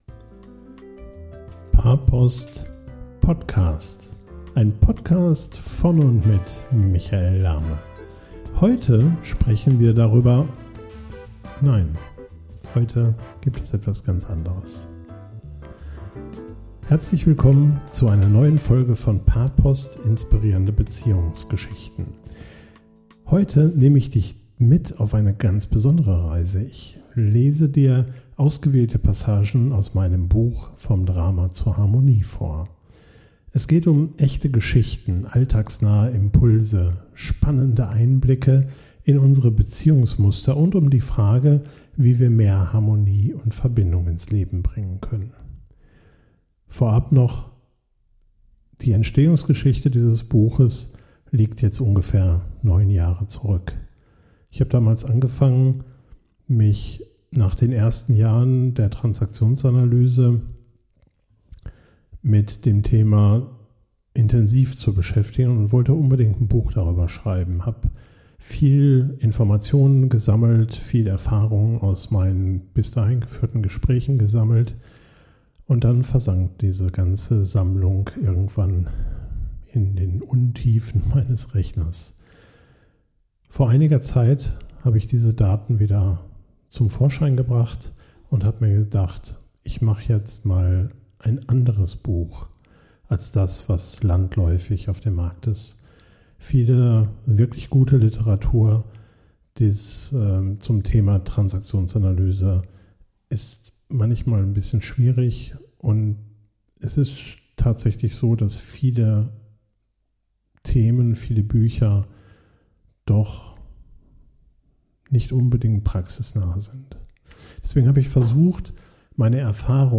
Es erwartet dich eine abwechslungsreiche Mischung aus Lesung, persönlicher Reflexion und kleinen Übungen zum Mitmachen.